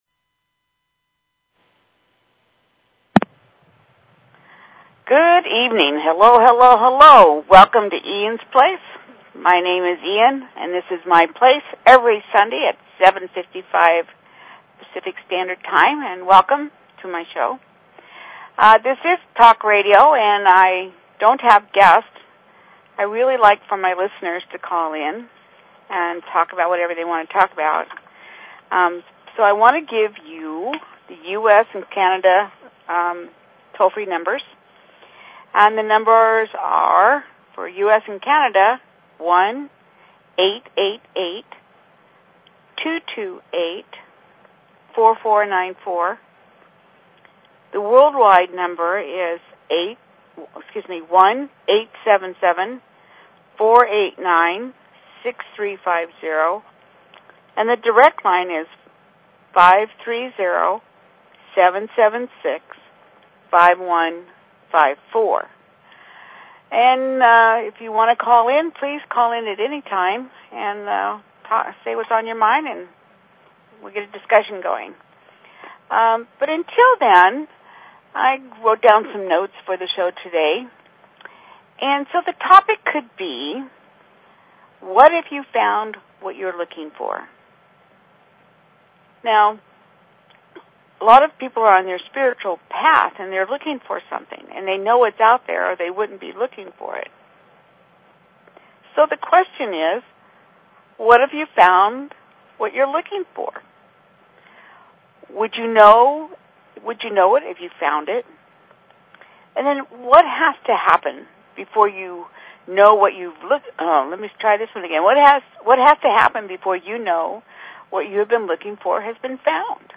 Talk Show Episode, Audio Podcast, Eans_Place and Courtesy of BBS Radio on , show guests , about , categorized as